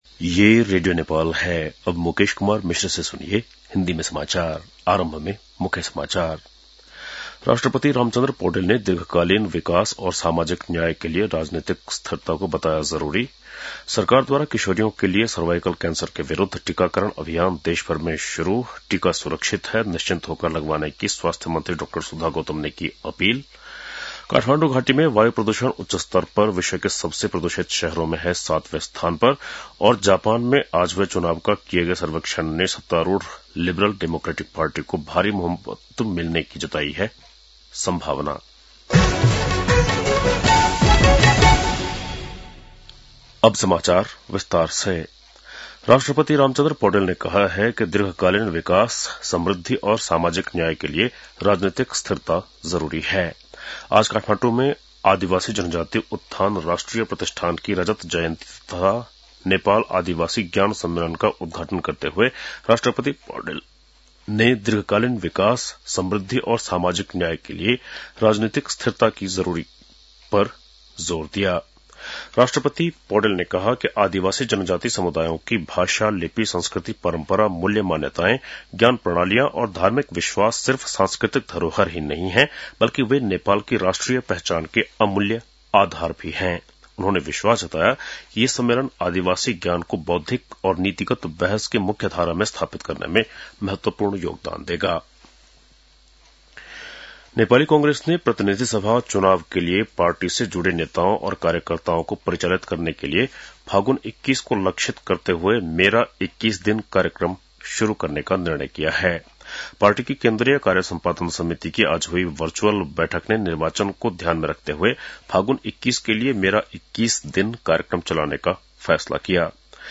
बेलुकी १० बजेको हिन्दी समाचार : २५ माघ , २०८२
10-pm-hindi-news-1-1.mp3